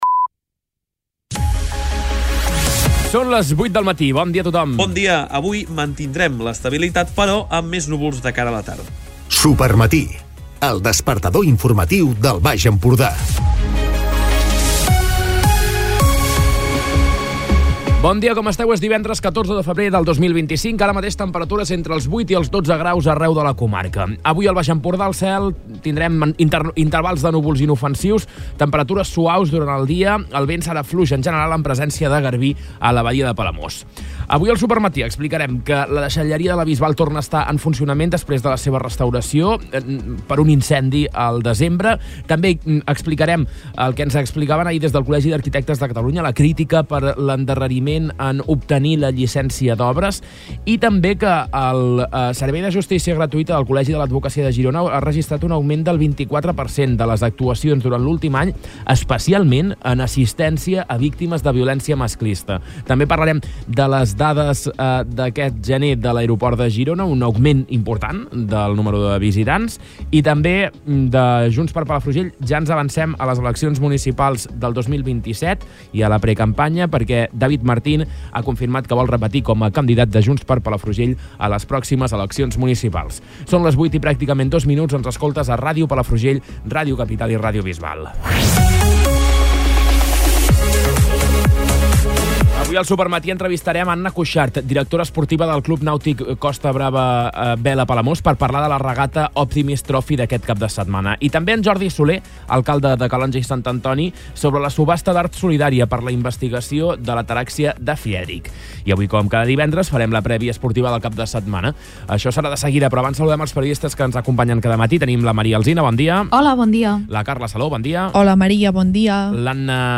Escolta l'informatiu d'aquest divendres